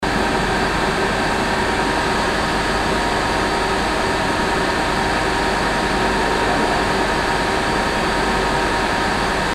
zvuk-proizvodstva_002
zvuk-proizvodstva_002.mp3